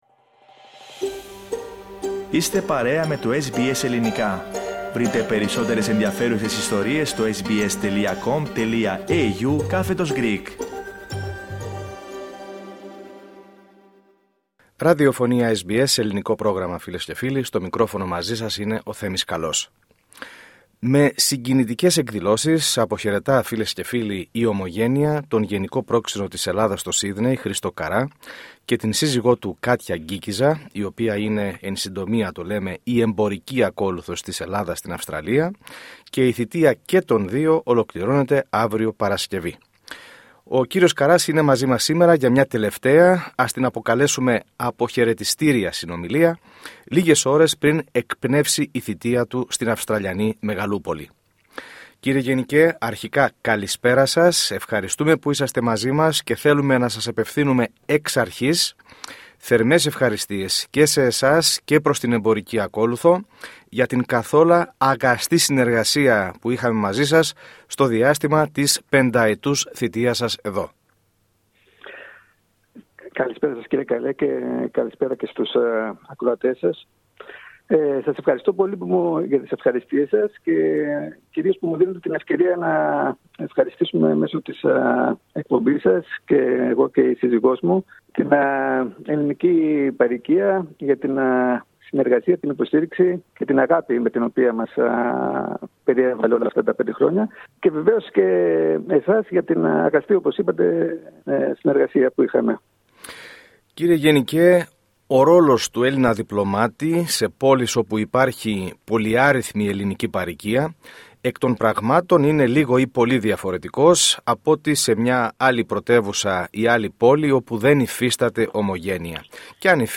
Παραχώρησε μια τελευταία, αποχαιρετιστήρια συνομιλία, στο SBS Greek, μ το οποίο η συνεργασία υπήρξε αγαστή καθ’ όλο το διάστημα της πενταετούς θητείας του.